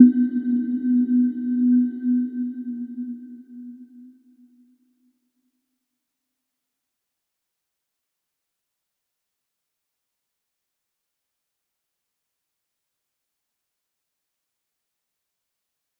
Little-Pluck-B3-mf.wav